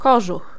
Ääntäminen
France: IPA: /po/ Belgique, Brabant wallon: IPA: [la po]